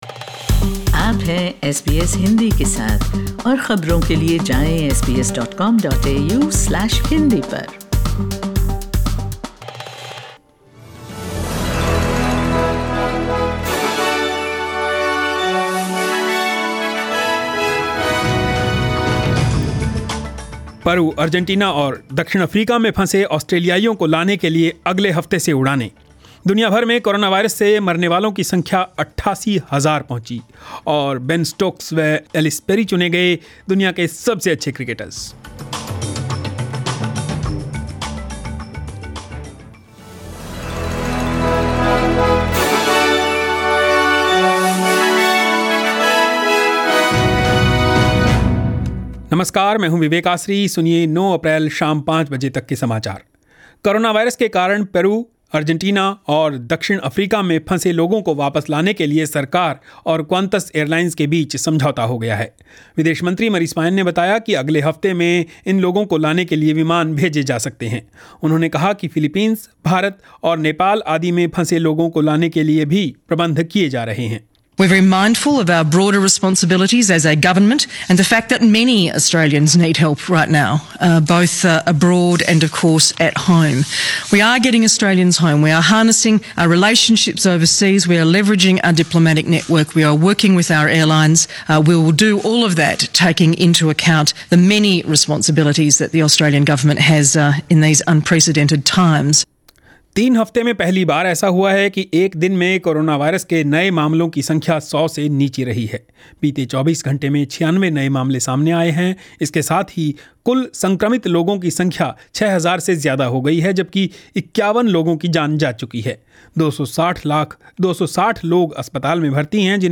News in Hindi 9 April 2020